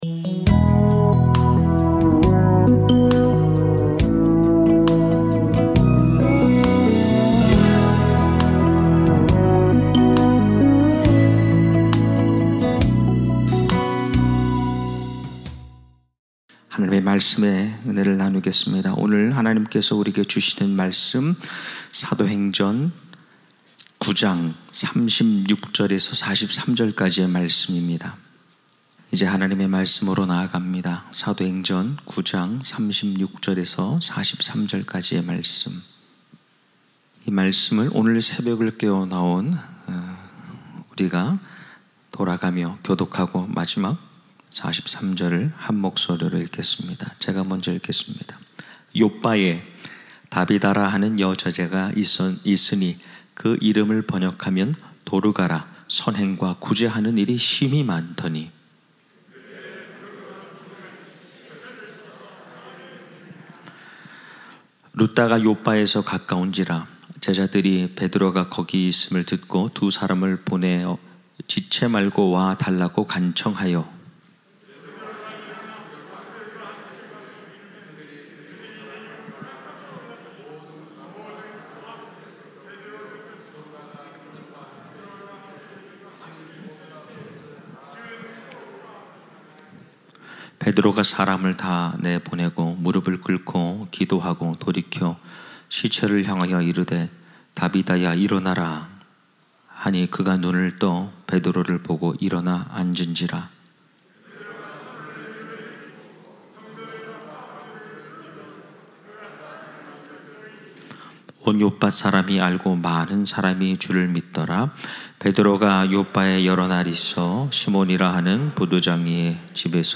특별 새벽 집회 – 나성영락 양육과정